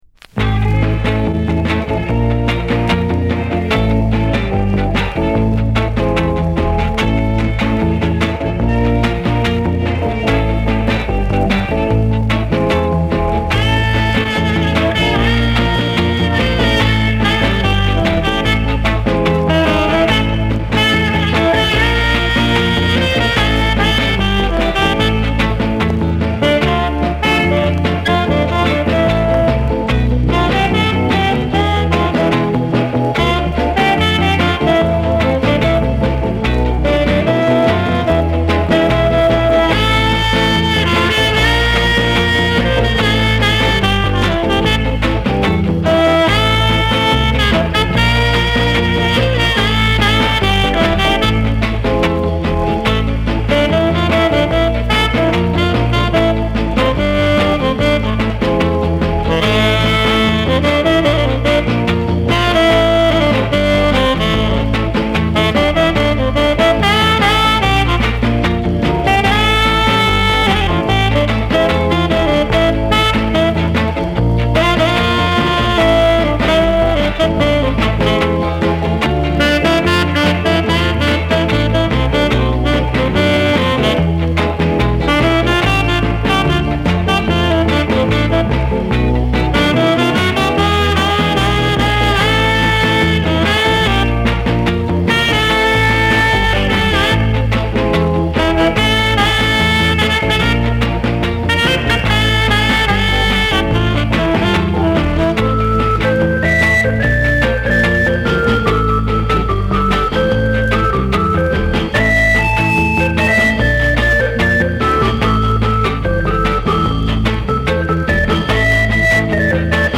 B面はタイトルほどラテンではないが、太く適度に枯れたサックス・プレイが楽しめる好ナンバー。